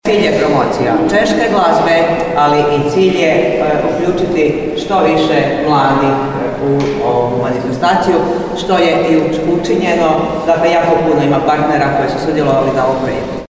U ime suorganizatora ovog programa prisutnima se obratila zamjenica gradonačelnika Veronika Pilat: